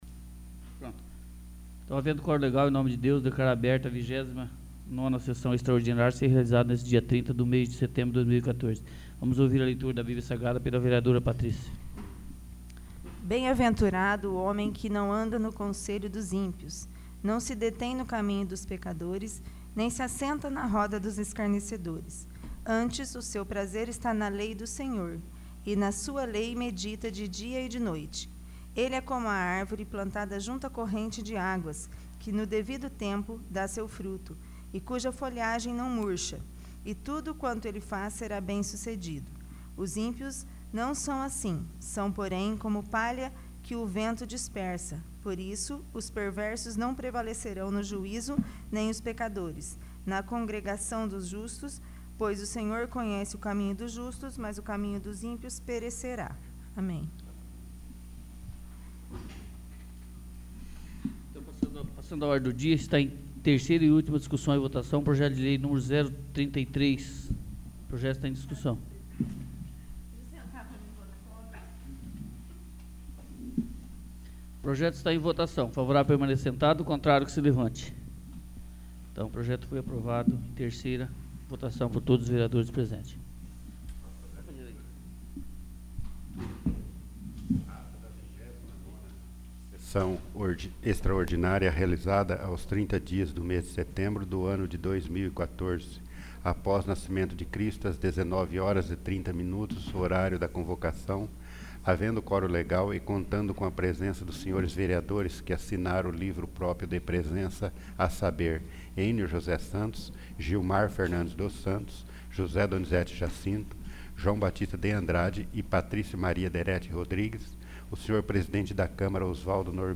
29º. Sessão Extraordinária